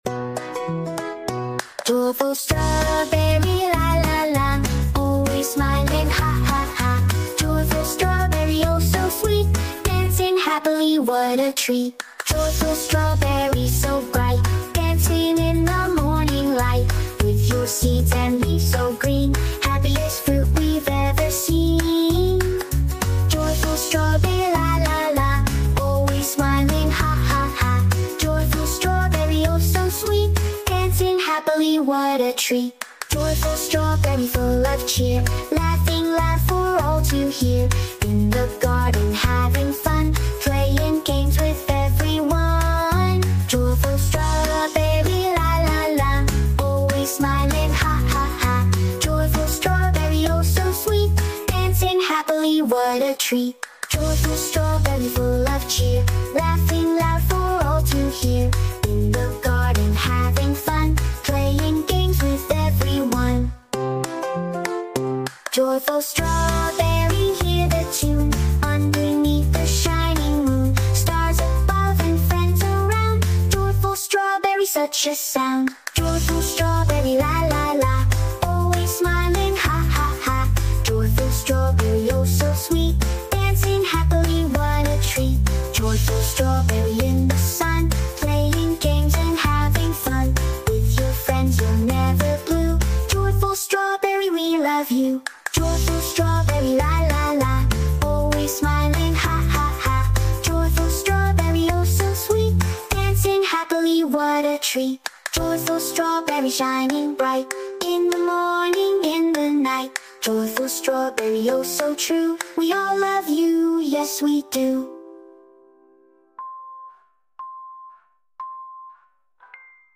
Nursery Rhymes & Kids Songs